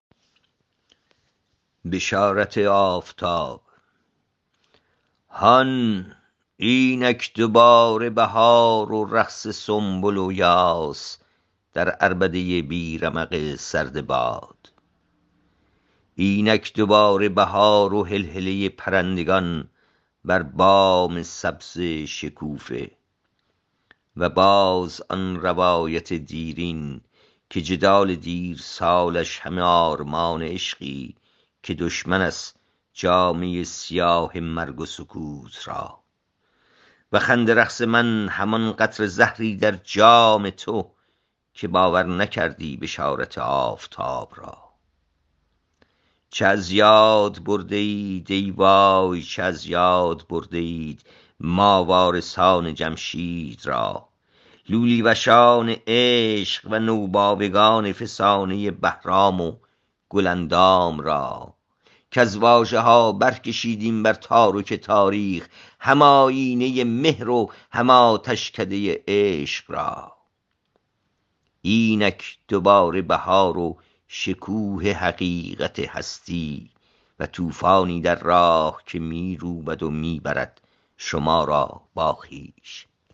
این شعر را با صدای شاعر از اینجا بشنوید